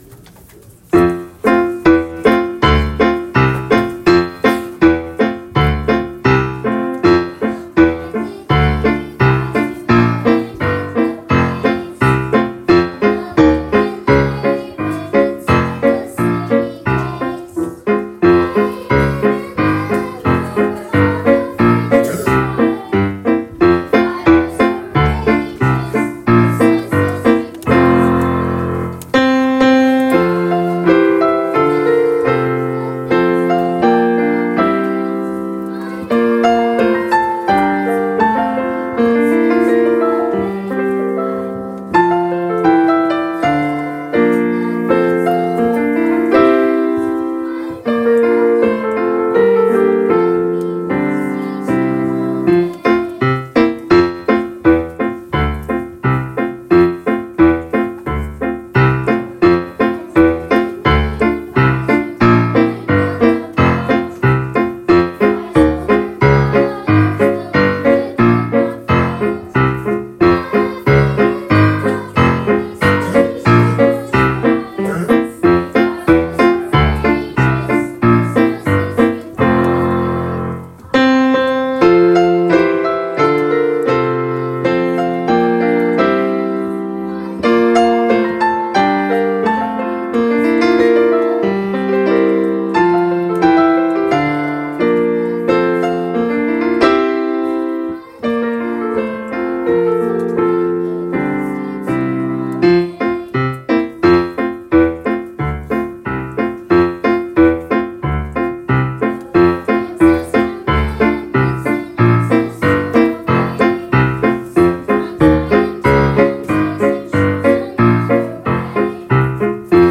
Voicing/Instrumentation: Primary Children/Primary Solo